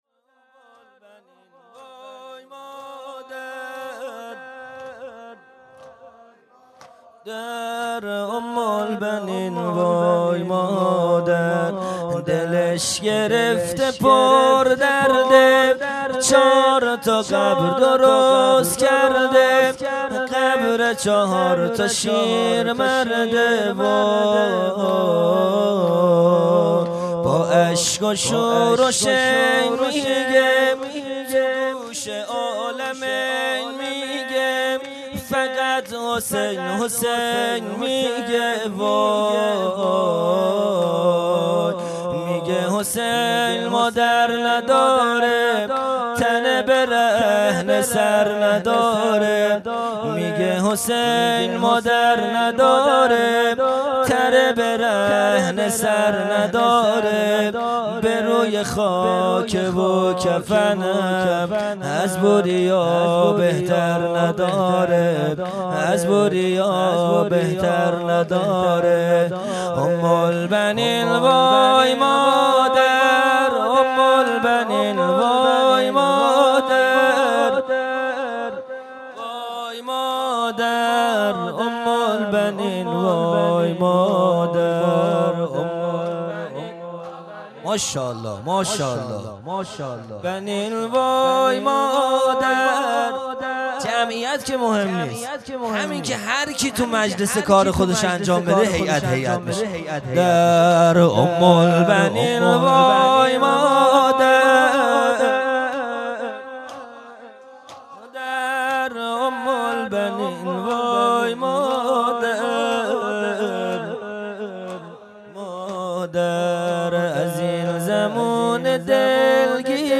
خیمه گاه - هیئت بچه های فاطمه (س) - زمینه | دلش گرفته پر درده
جلسۀ هفتگی | به مناسبت وفات حضرت ام البنین(س)